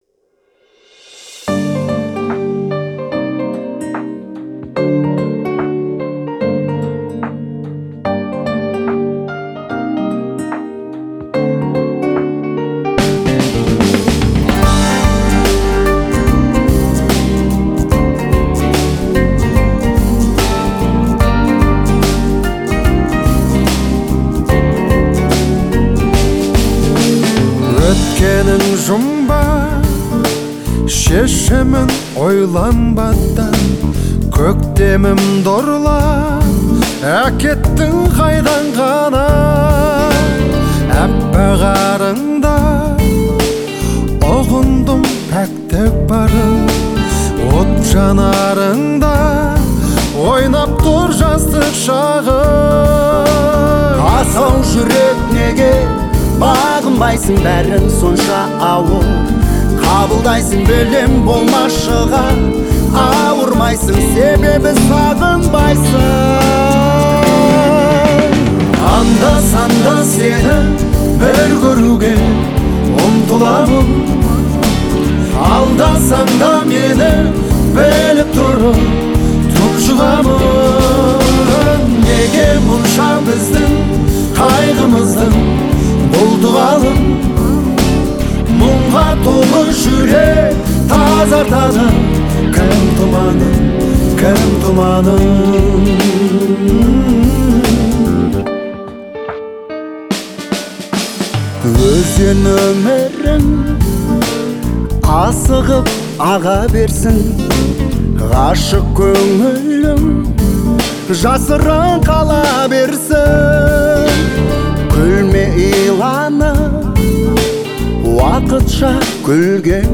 это яркая и эмоциональная песня в жанре казахского поп-фолка